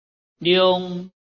臺灣客語拼音學習網-客語聽讀拼-海陸腔-鼻尾韻
拼音查詢：【海陸腔】liung ~請點選不同聲調拼音聽聽看!(例字漢字部分屬參考性質)